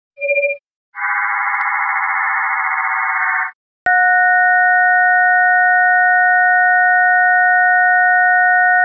Sinal não identificado nos 40m
Há tempos uma portadora na banda dos 40m vem causando interferência (impede o uso daquele seguimento) e é assunto recorrente entre os radioamadores brasileiros. Trata-se de um sinal muito forte, ouvido praticamente em todo terriório nacional, com 10KHz de largura de banda na frequência de 7.170 MHz. As vezes era uma transmissão analógica, com música, mas nas últimas semanas mudou para digital e permanece assim.